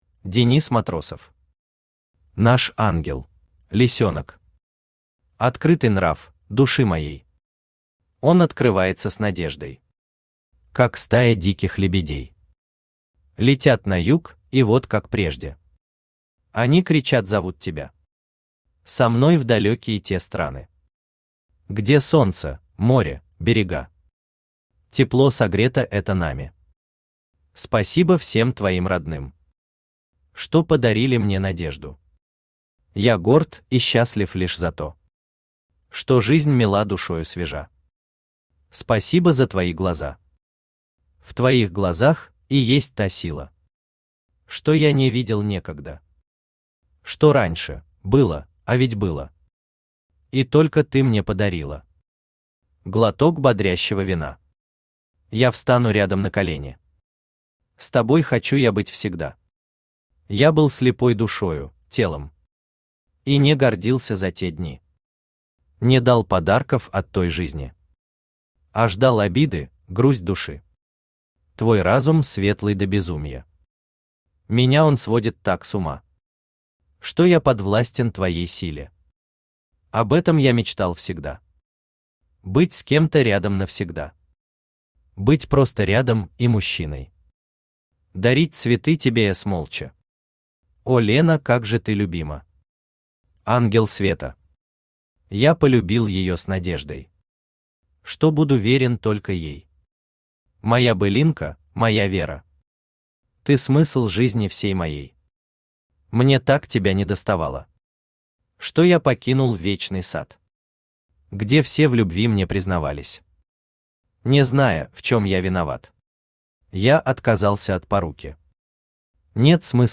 Аудиокнига Наш ангел | Библиотека аудиокниг
Aудиокнига Наш ангел Автор Денис Матросов Читает аудиокнигу Авточтец ЛитРес.